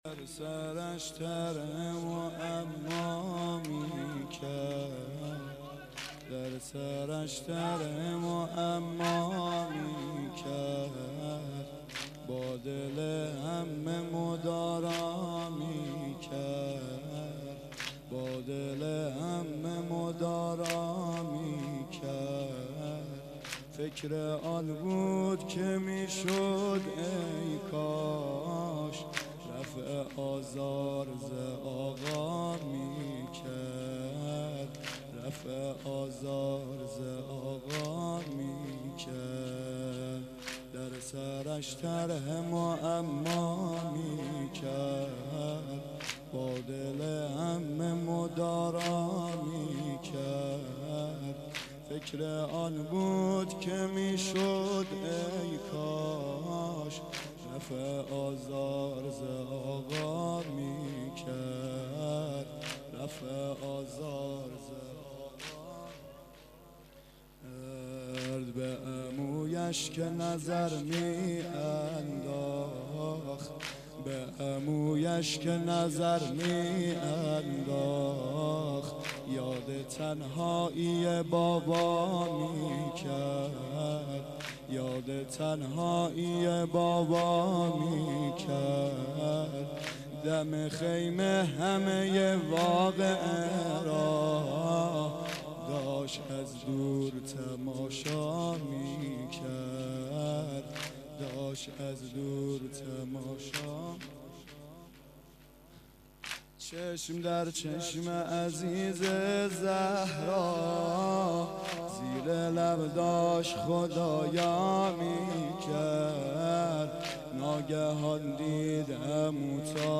واحد شب پنجم محرم1391